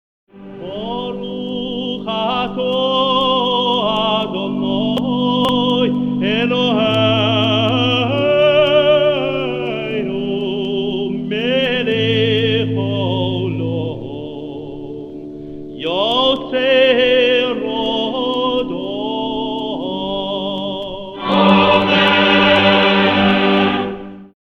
3e bénédiction (rite ashkenaze
accompagné à l’orgue